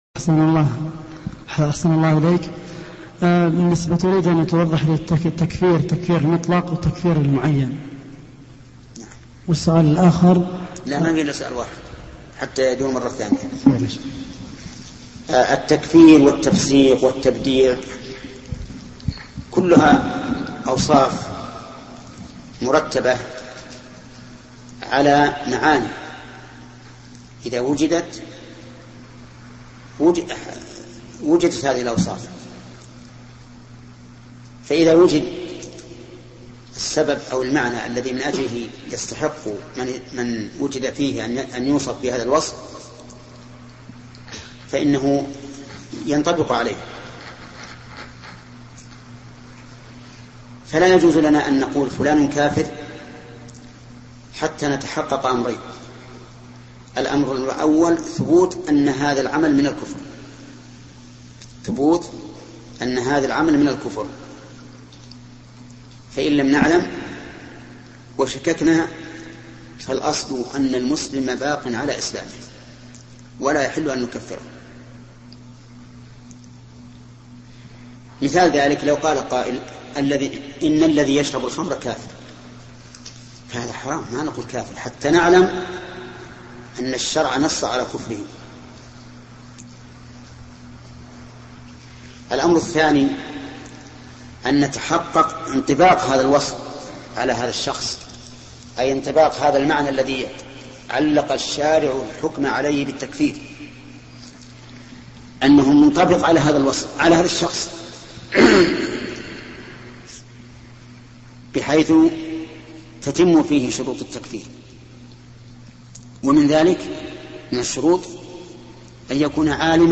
Download audio file Downloaded: 714 Played: 1961 Artist: الشيخ ابن عثيمين Title: الفرق بين التكفير المطلق وتكفير المعين Album: موقع النهج الواضح Length: 7:15 minutes (1.74 MB) Format: MP3 Mono 22kHz 32Kbps (VBR)